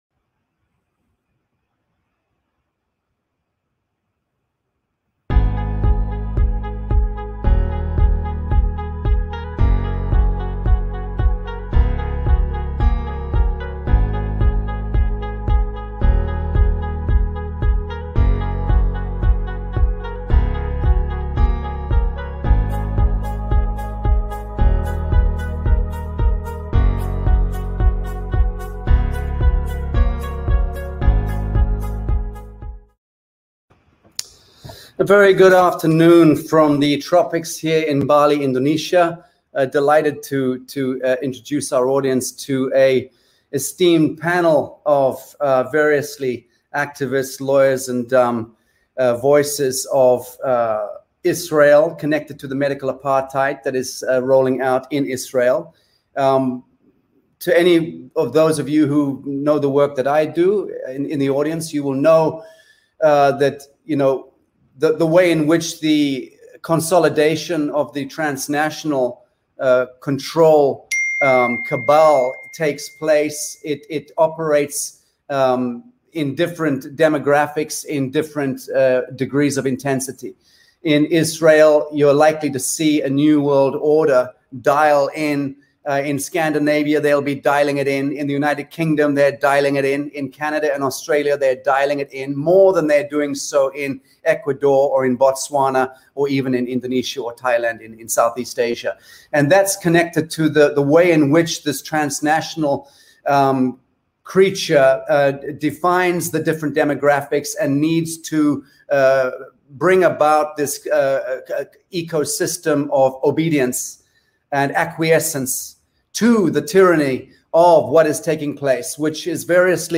Emergency Live by a panel of experts - LIVESTREAM ISRAEL